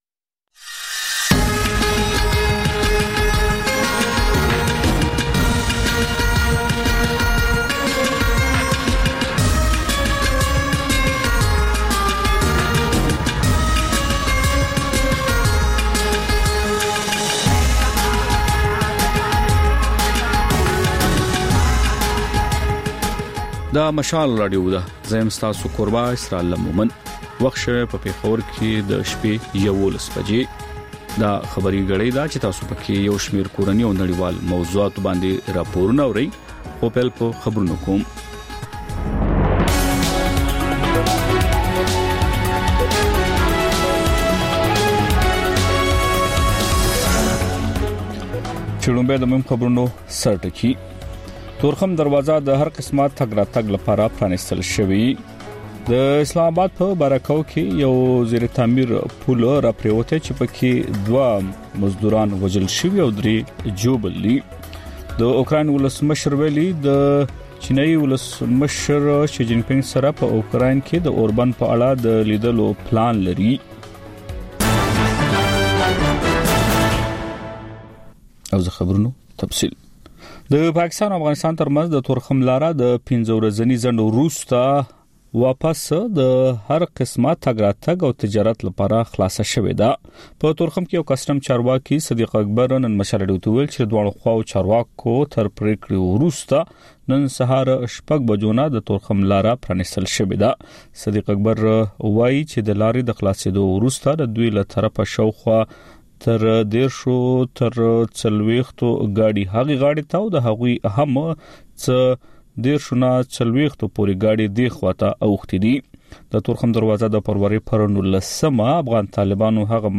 دلته د مشال راډیو د سلام پښتونخوا خپرونې تکرار اورئ. په دې خپرونه کې تر خبرونو وروسته رپورټونه خپروو او پکې د سیمې اوسېدونکو د خپلو کلیو او ښارونو تازه او مهم خبرونه راکوي. په خپرونه کې سندرې هم خپرېږي.